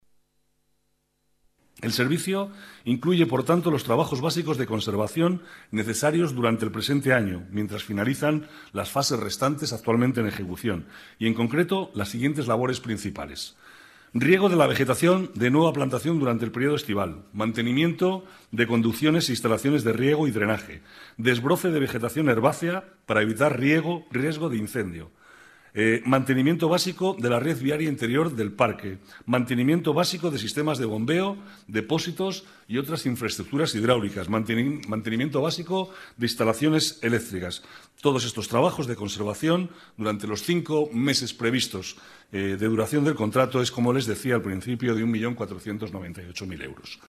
Nueva ventana:Manuel Cobo, vicealcalde, sobre el mantenimiento de Valdebebas